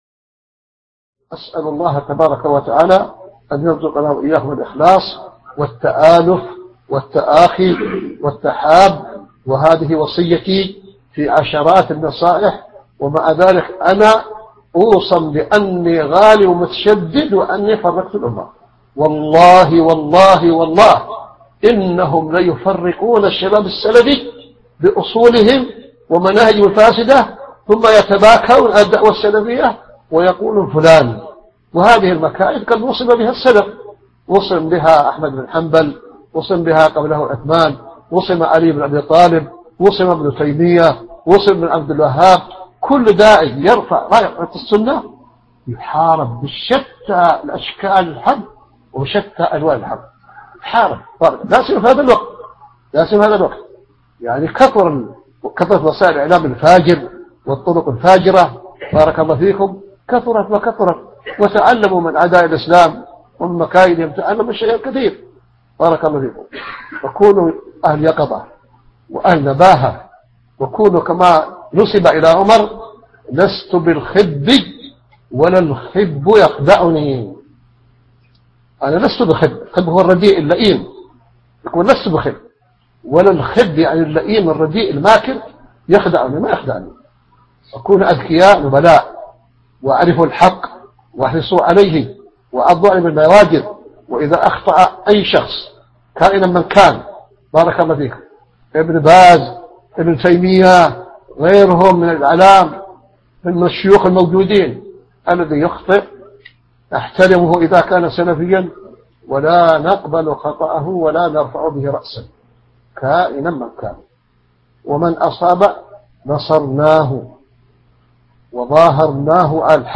القسم: من مواعظ أهل العلم
Format: MP3 Mono 22kHz 32Kbps (VBR)